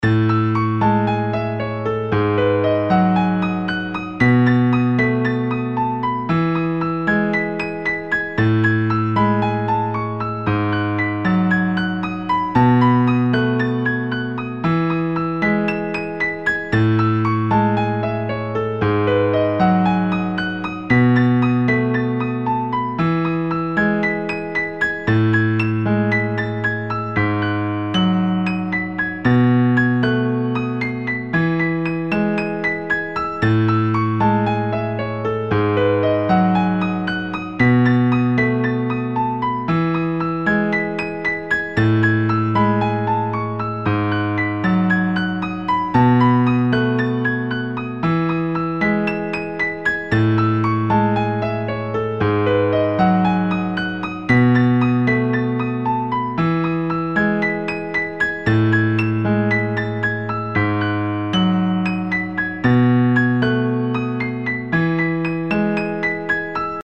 音楽ジャンル： アコースティック
楽曲の曲調： SOFT
稲穂が風で揺れている様なシーンのBGMに